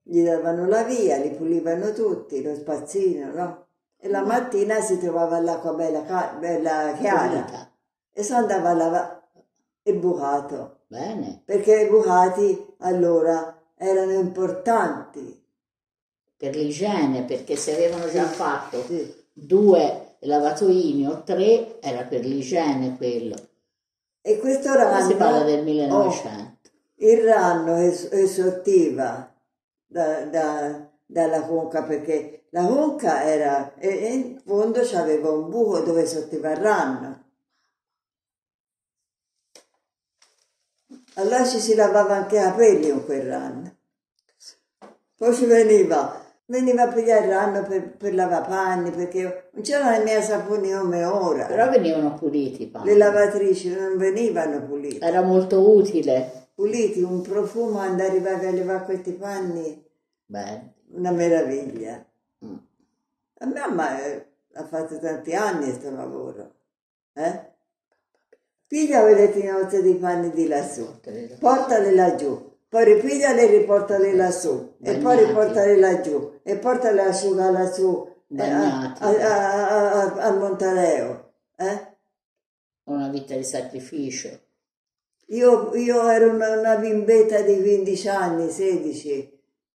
Testimonianza audio